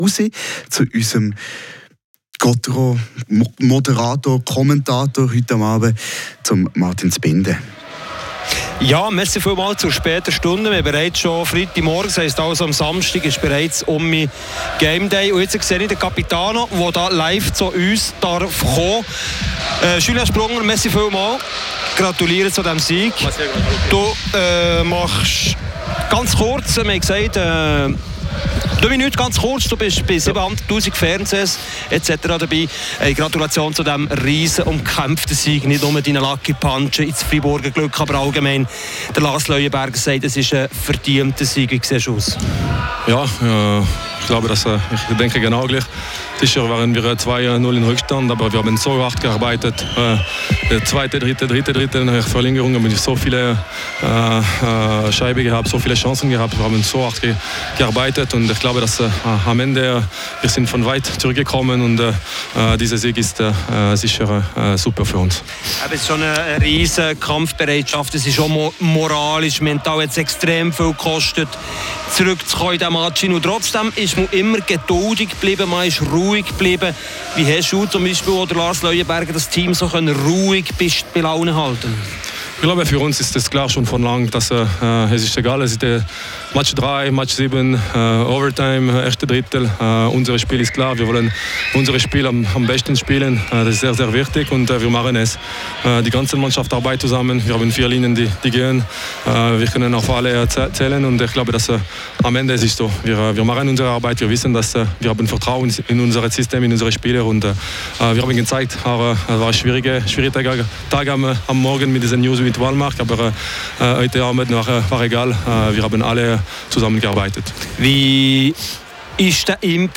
Spielanalyse
Interviews